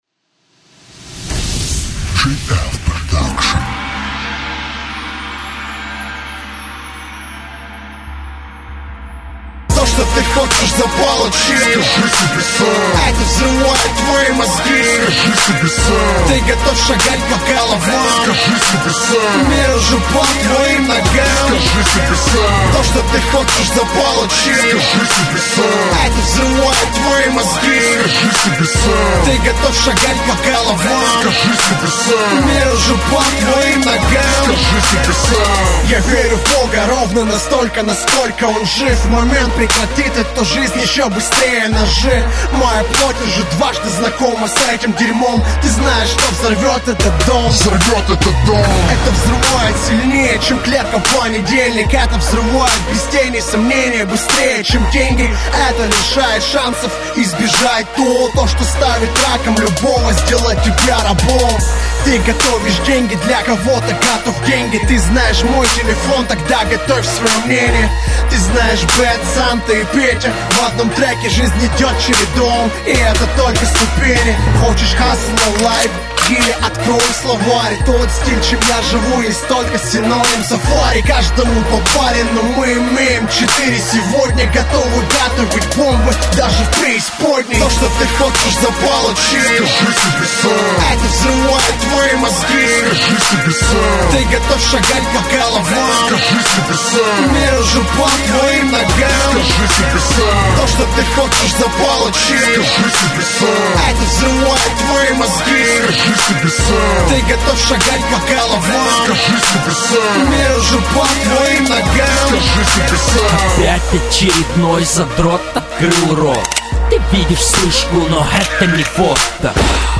Категория: РэпЧина